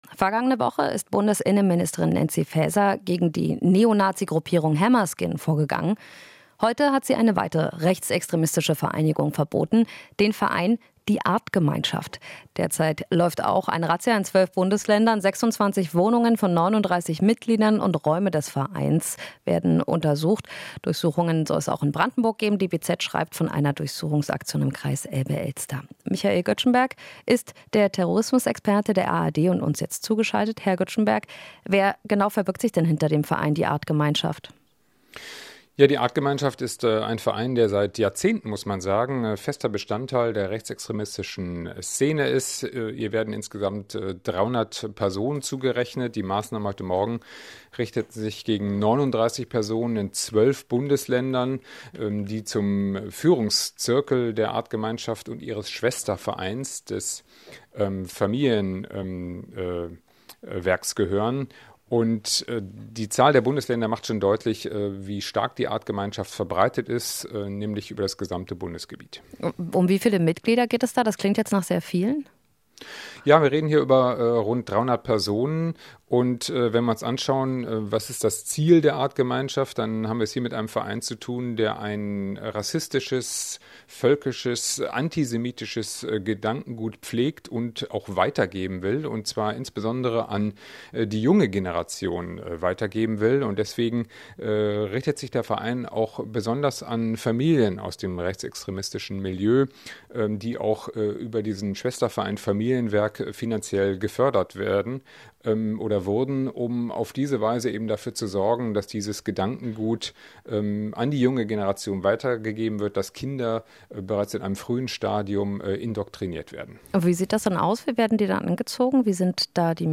Interview - Innenministerin Faeser verbietet Neonazi-Verein "Artgemeinschaft"